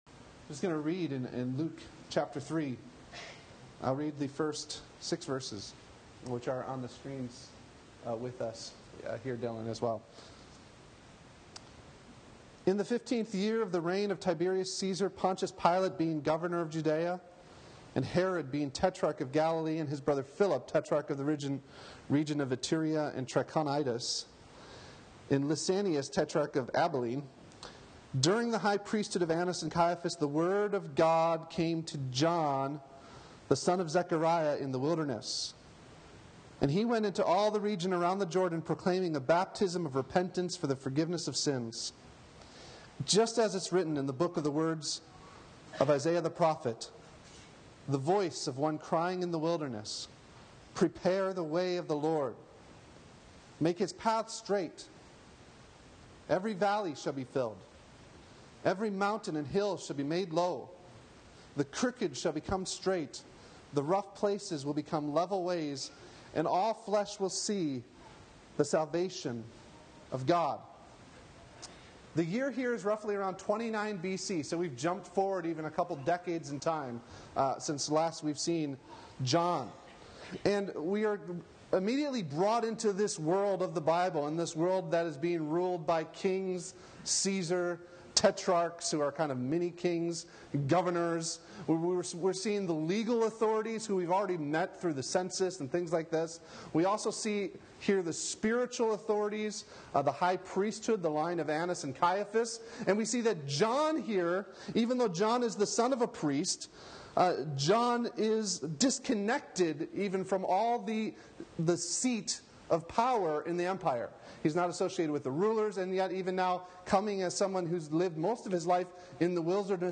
Audio Link Below With Baptism Testimonies!